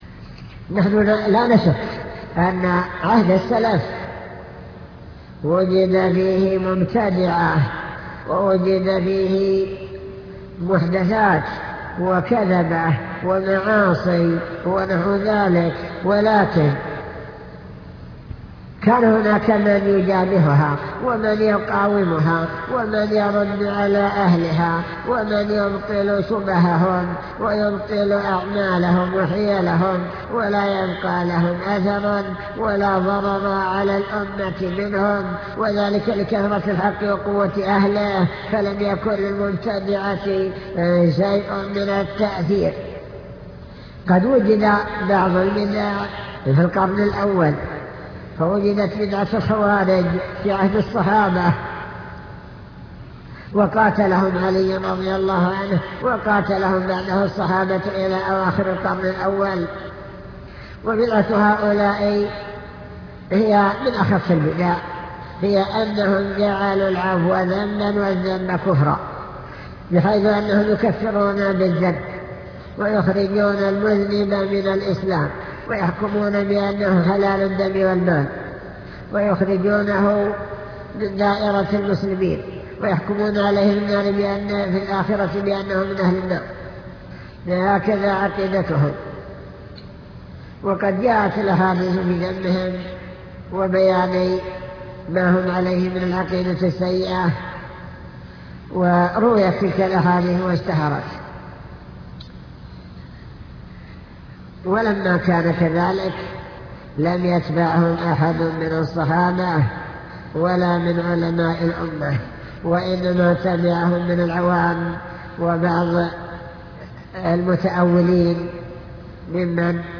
المكتبة الصوتية  تسجيلات - محاضرات ودروس  السلف الصالح بين العلم والإيمان